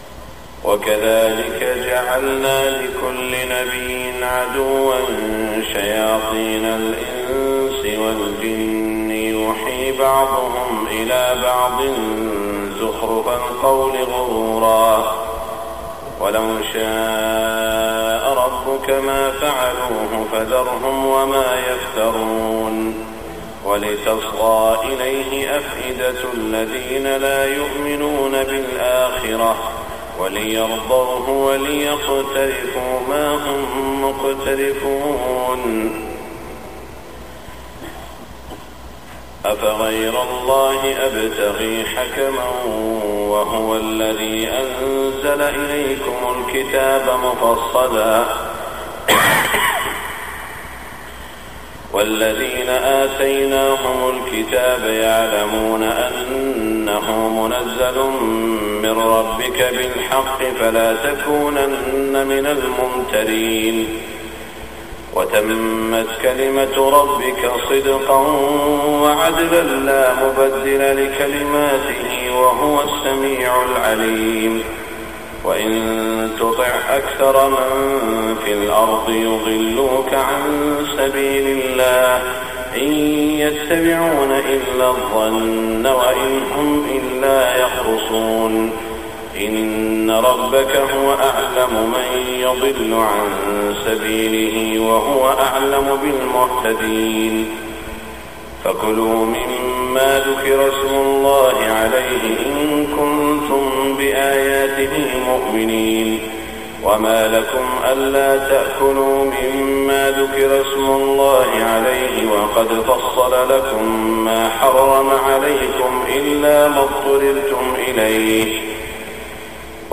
صلاة الفجر١٤٢٤ من سورة الأنعام > 1424 🕋 > الفروض - تلاوات الحرمين